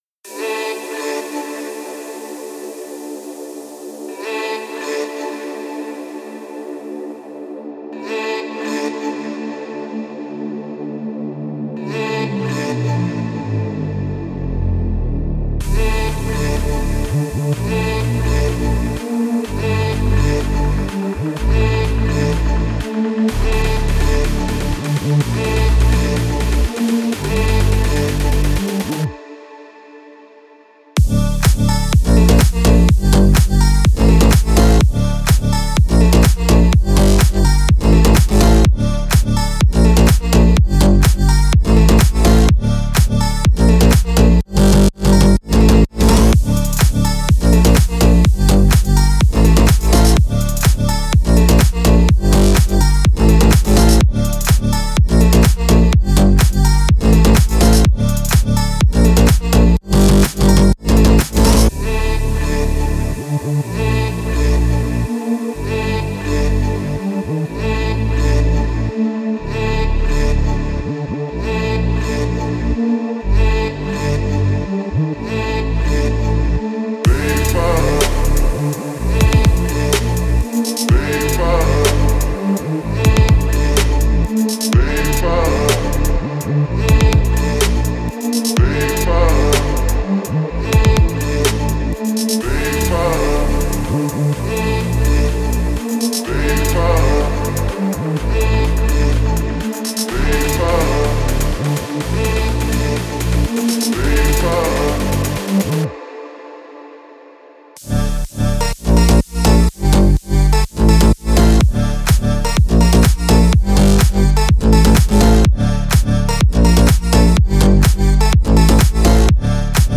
это энергичный трек в жанре хип-хоп